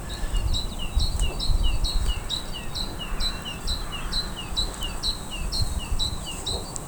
Vogelstimmen: Zaunkönig,
Kohlmeise,
kohlmeise.wav